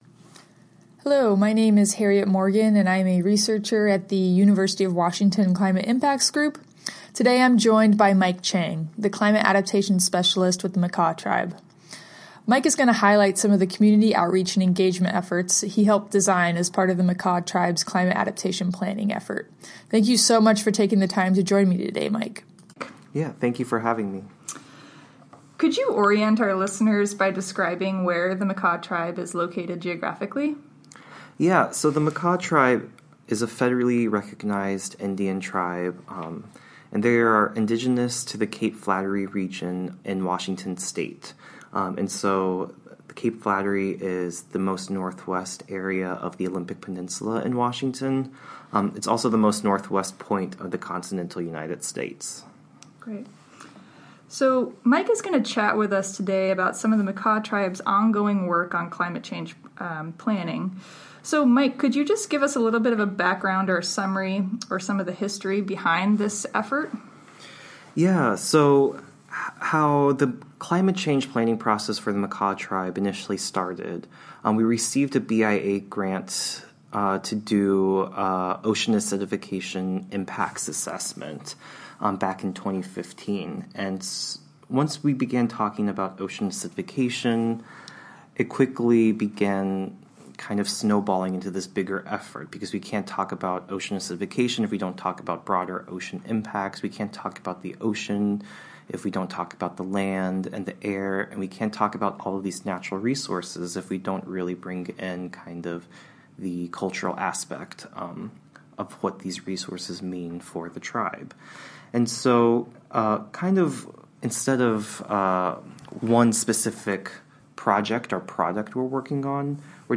Makah-Tribal-Engagement-Audio.mp3